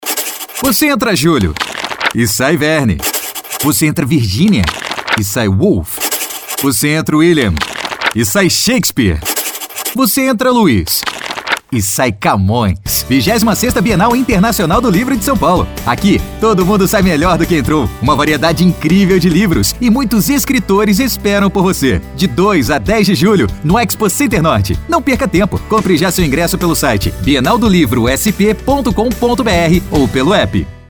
bienal_do_livro_-_spot_de_radio.mp3